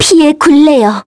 Lewsia_A-Vox_Skill3-2_kr.wav